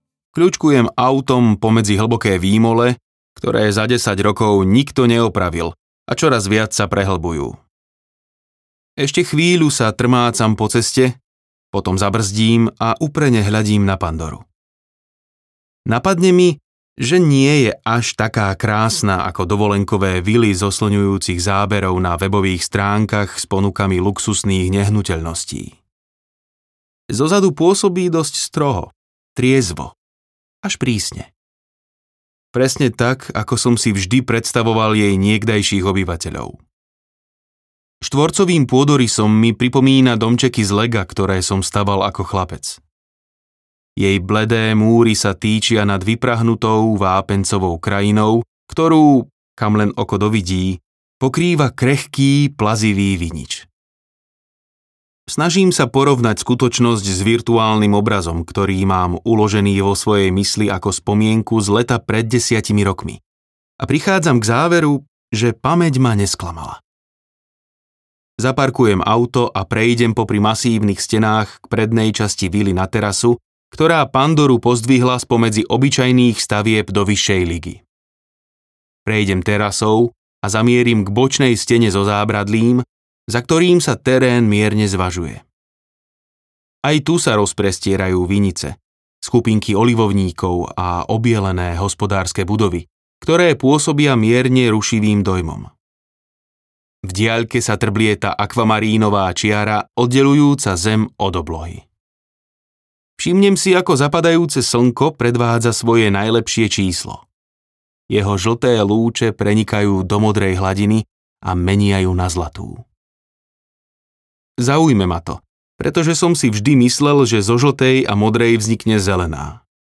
Olivovník audiokniha
Ukázka z knihy